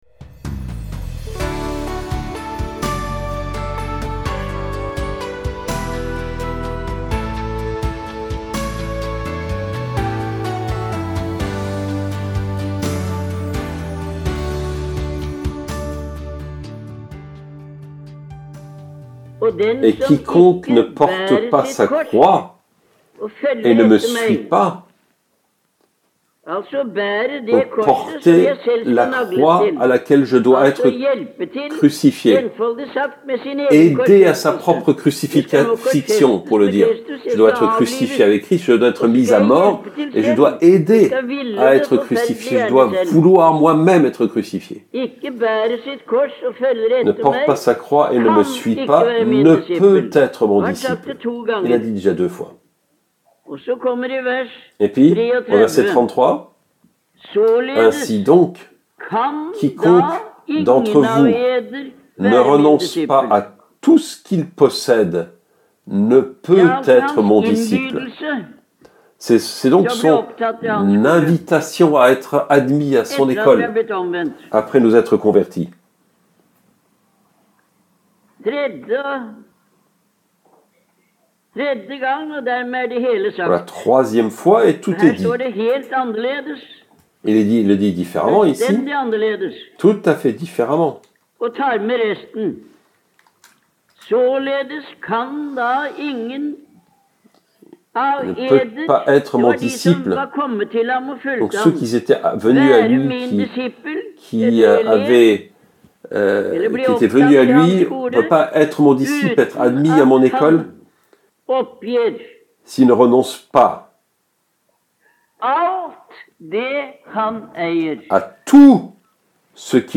audio/Tema%202/fr/10%20T%20-Oppgi%20alt_FR.mp3 Click to listen to the speech.